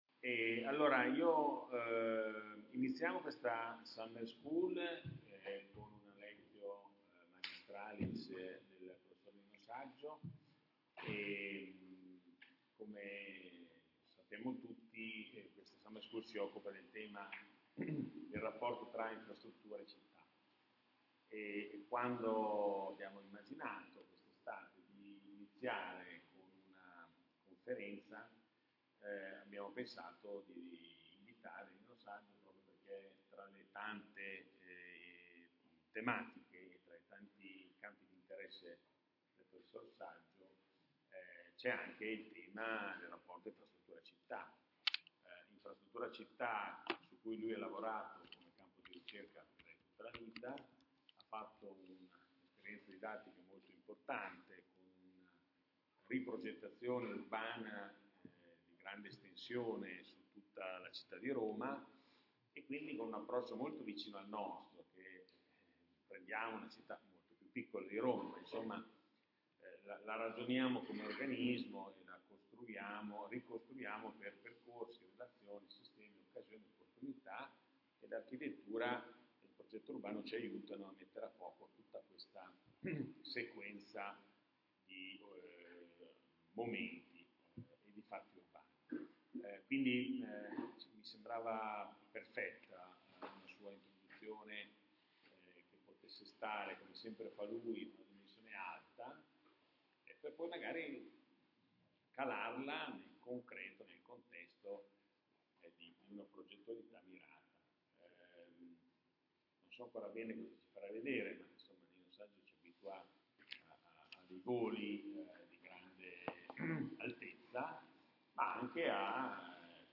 Ascolta l'Audio completo della lectio